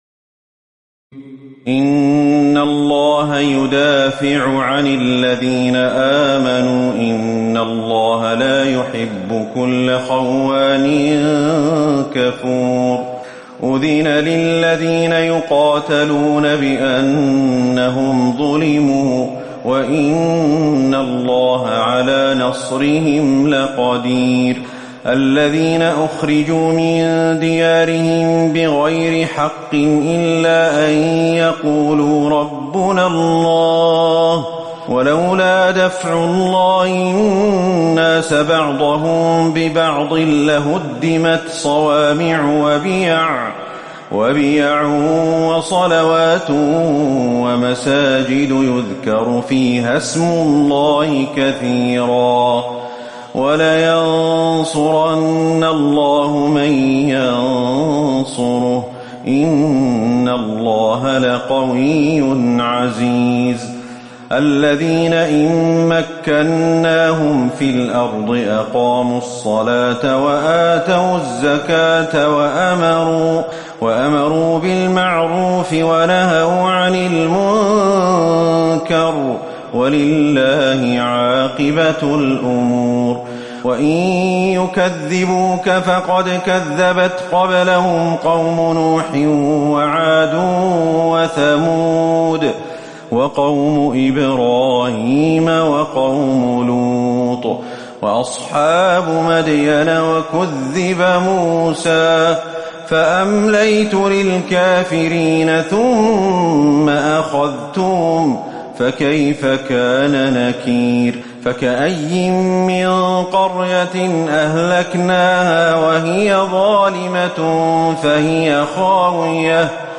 تراويح الليلة السابعة عشر رمضان 1438هـ من سورتي الحج (38-78) المؤمنون كاملة Taraweeh 17 st night Ramadan 1438H from Surah Al-Hajj and Al-Muminoon > تراويح الحرم النبوي عام 1438 🕌 > التراويح - تلاوات الحرمين